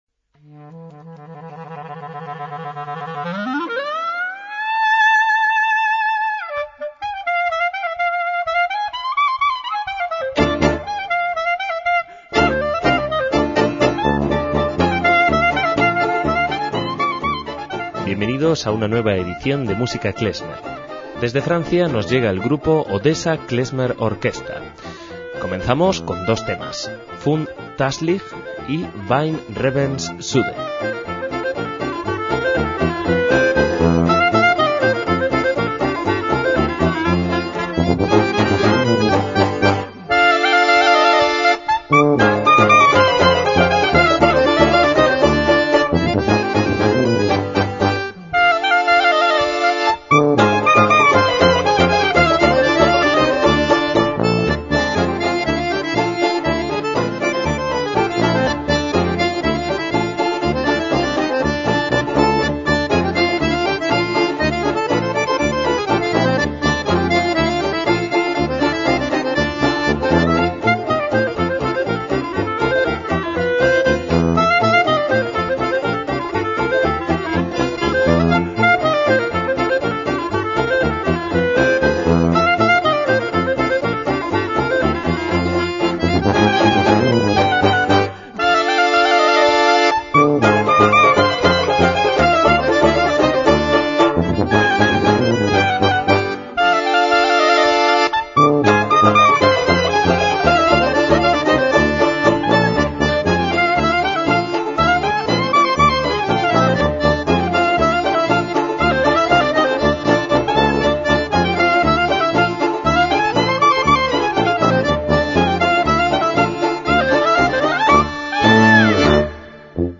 MÚSICA KLEZMER
es un grupo francés de klezmer